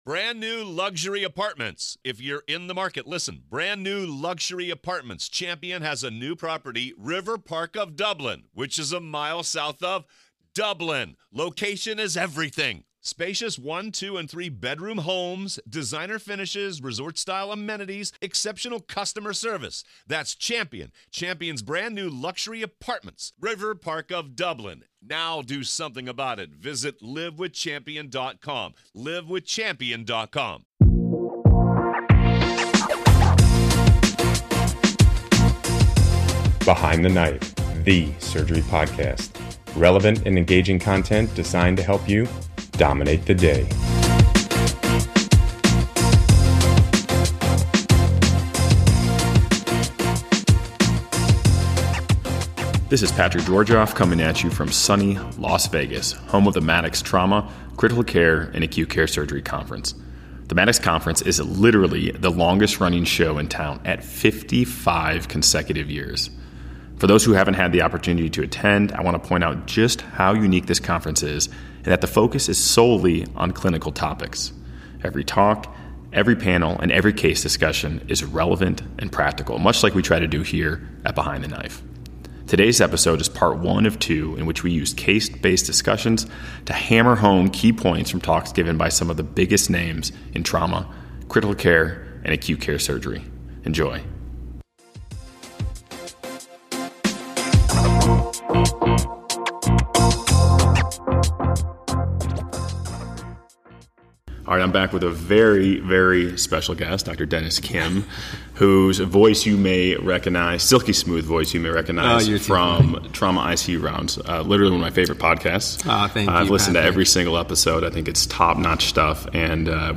Join Behind the Knife as we use case-based discussions to hammer home key points from lectures given by some of the biggest names in trauma, critical care, and acute care surgery.